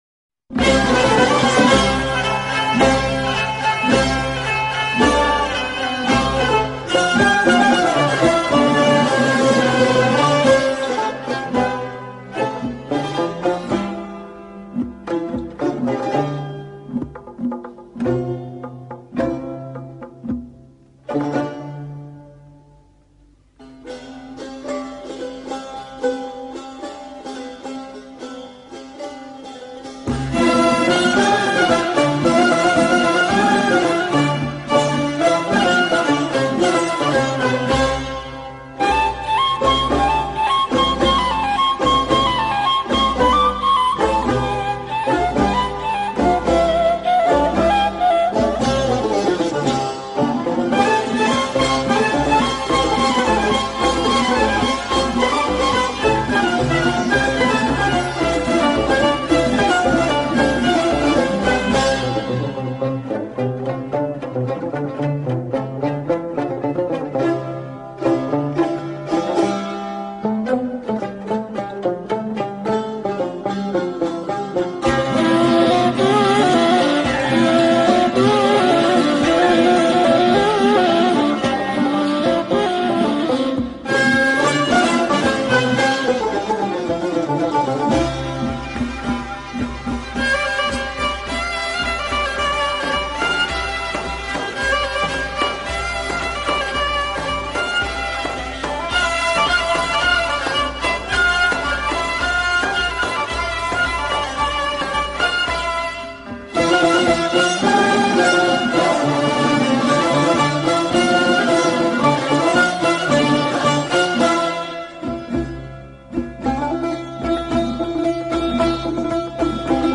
موسیقی سنتی
نی